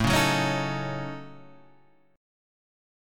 A6b5 chord